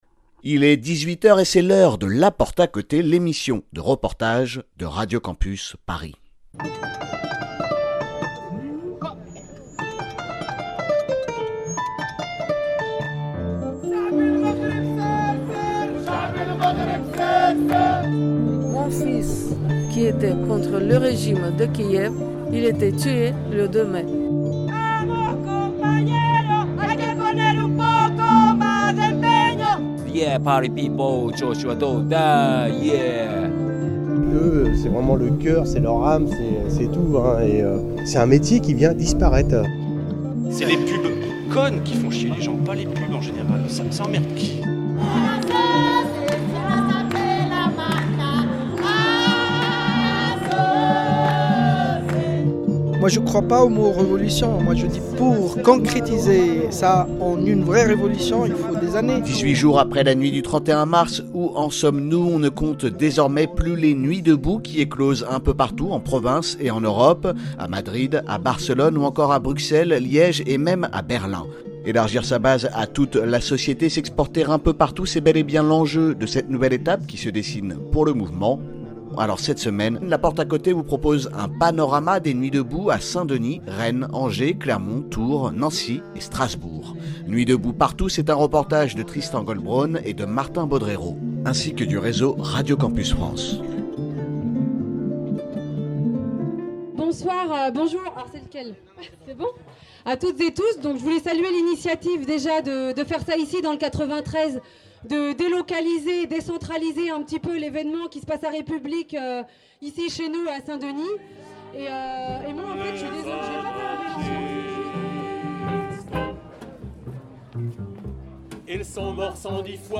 La Porte à Côté c'est l'émission de reportage de Radio Campus Paris chaque lundi à 18h sur le 93.9 FM.